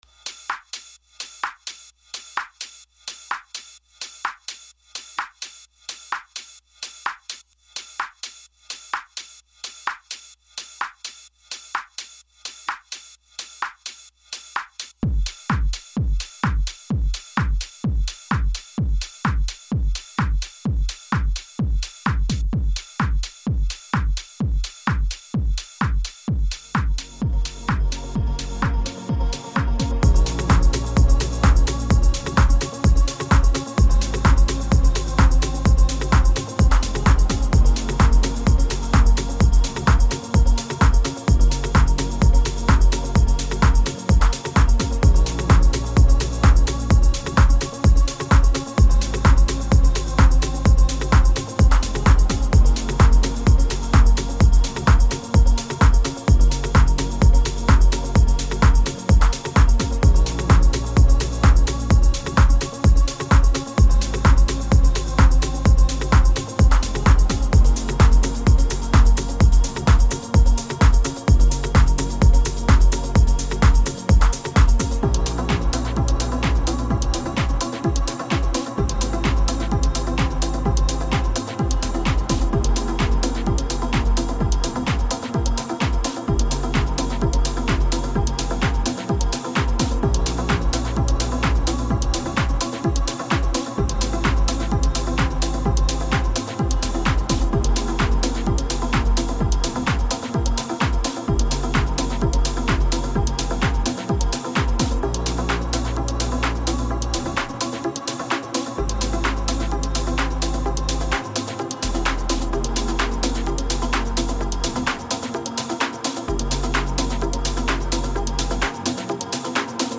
• Version Instrumental
This is the instrumental.
Tempo 128BPM (Allegro)
Genre Chillout House
Type Instrumental
Mood Energetic